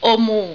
Oh Mu (OH-moo) AIFF,